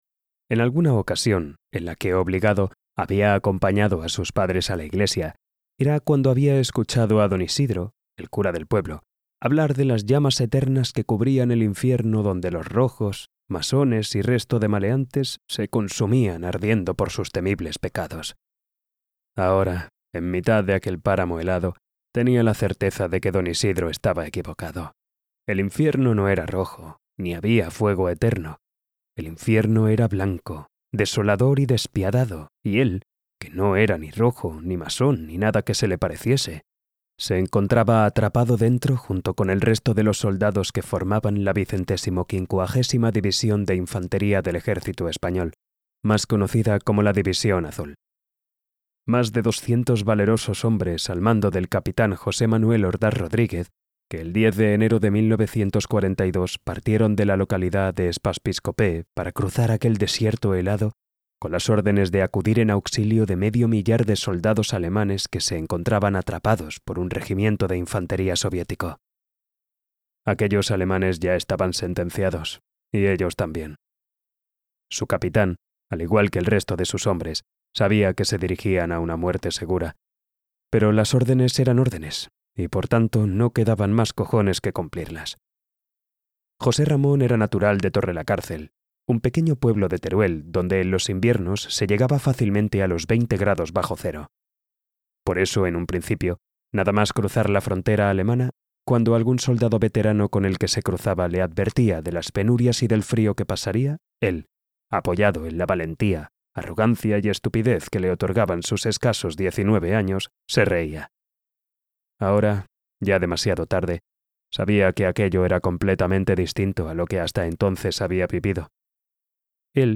Audiolibro El asesino de mariposas (The Butterfly Assassin)